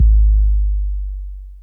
puredata/resources/808_drum_kit/kicks/808-Kicks18.wav at 5ee54f3b82a22d9e1776ce248abaf6beeac247ec
808-Kicks18.wav